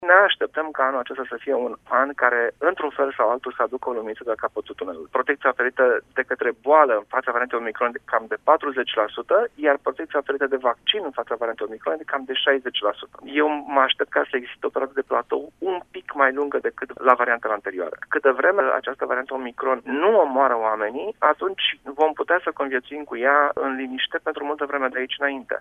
Medicul